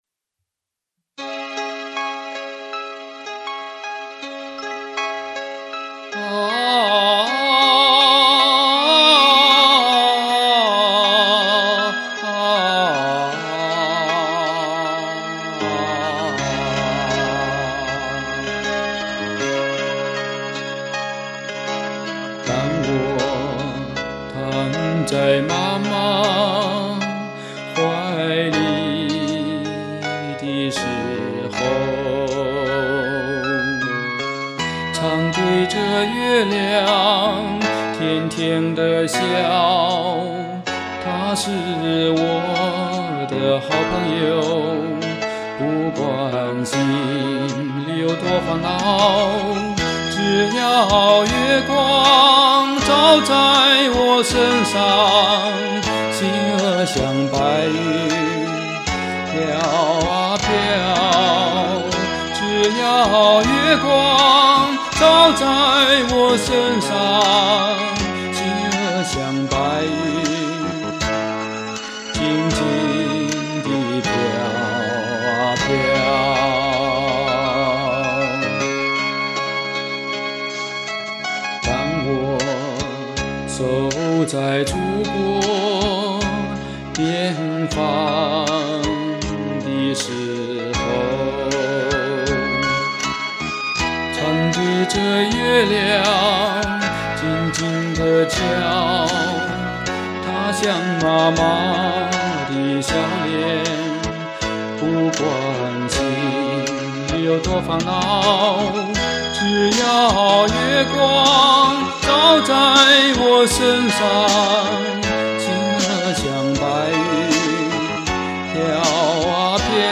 唱在母親節。